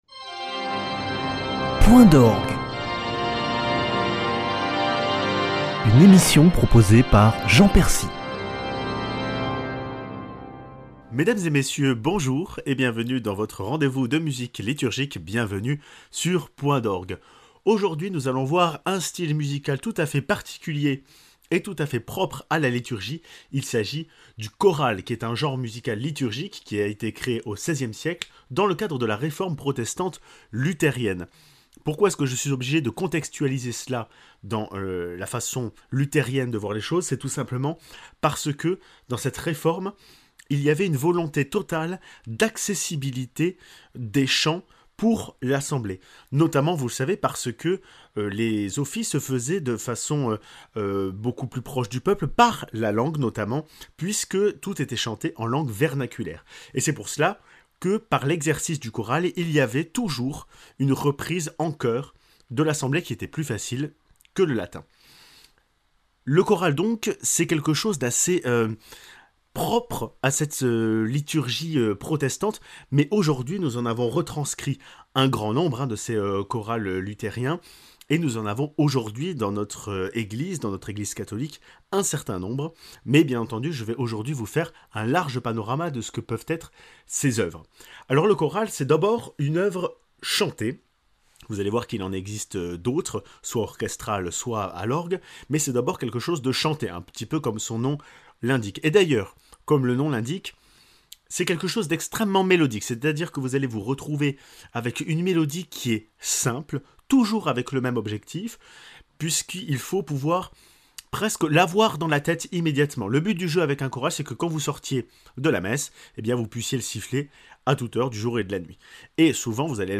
Genre liturgique par excellence, le choral existe vocalement, à l'orgue et aux instruments !